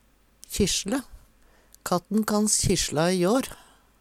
kjissjle - Numedalsmål (en-US)